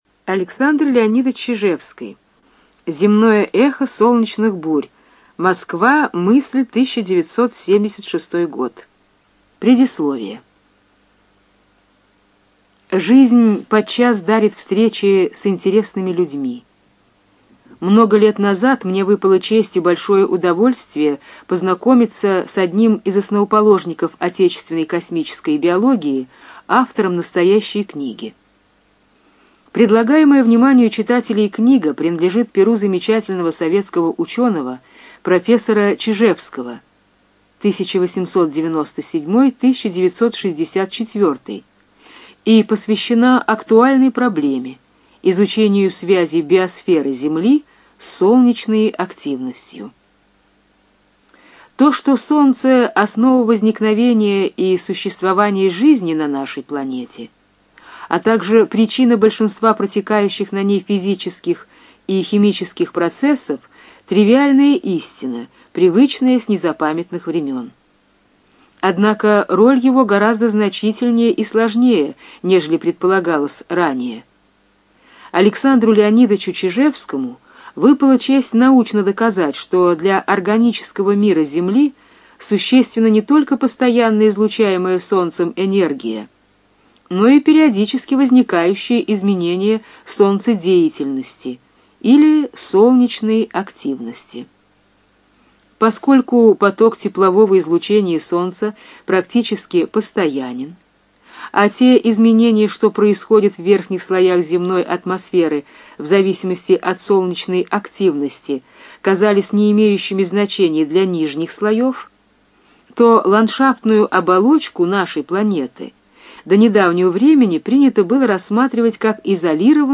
Студия звукозаписиЛогосвос